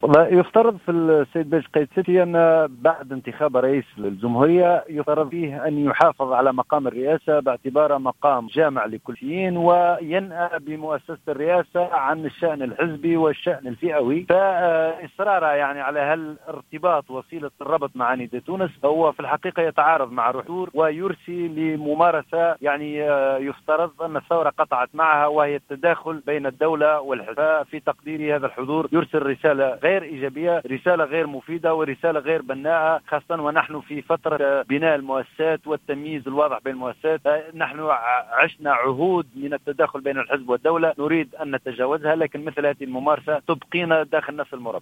وقال في تصريح لـ "الجوهرة اف أم" في تعليقه على إشراف الباجي قايد السبسي اليوم على مؤتمر لـ"نداء تونس"، إن إصراره على الارتباط بحزب نداء تونس يتعارض مع الدستور واعتبره تداخلا بين الدولة والحزب ورسالة غير ايجابية وغير مفيدة وغير بناءة، بحسب تعبيره.